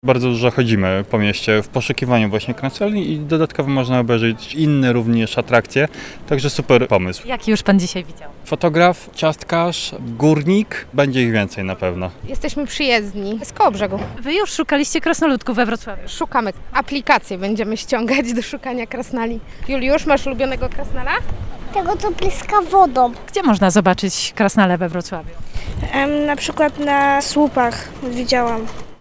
Chętnych do szukania skrzatów nie brakuje. Spotkaliśmy ich na Ostrowie Tumskim, przy Pergoli i w Rynku.